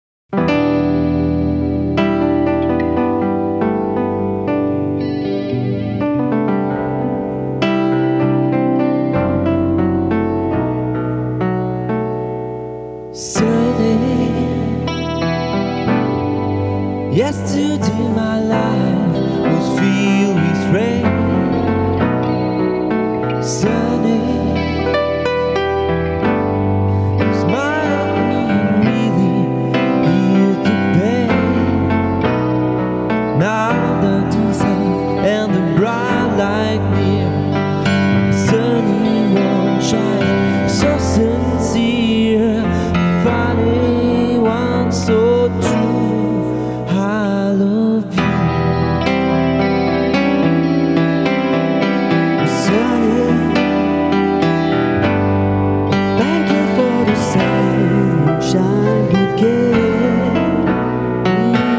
Le 05 et 06/08/2006 au studio "Mafaldine", Lamalou, France
Guitare
Claviers, Chant, Choeurs